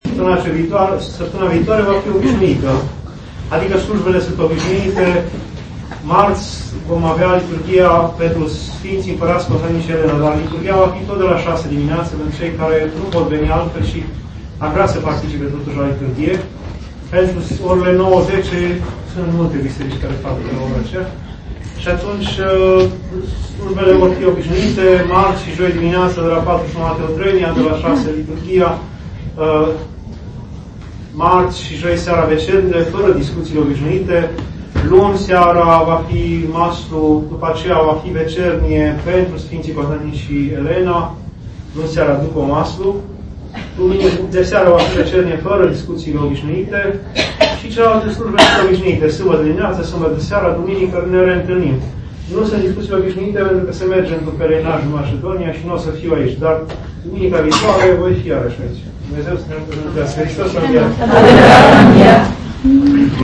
Aici puteți asculta și descărca înregistrări doar de la Bisericuța din Hașdeu